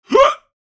Download Free Hiccup Sound Effects | Gfx Sounds
Elderly-man-hiccup.mp3